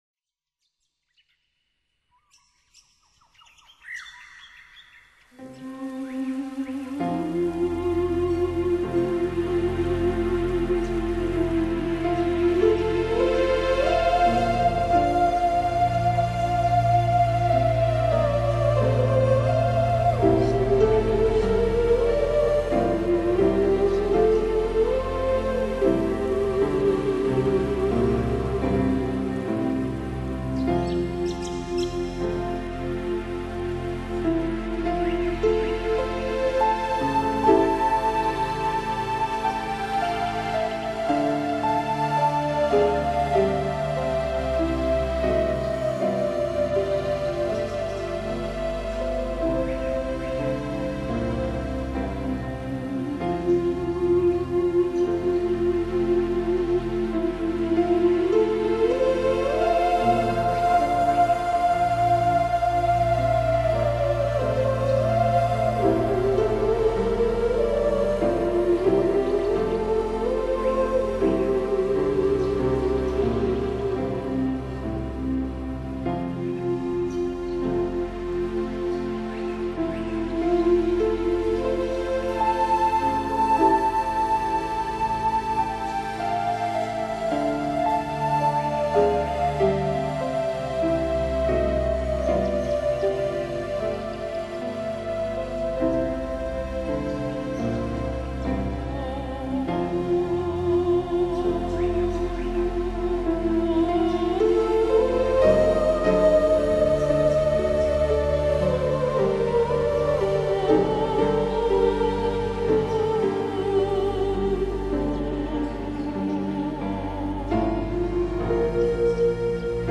疗效独特的保健音乐会消除你身心的疲惫。
这是一种治疗音乐，也是一种美妙音乐。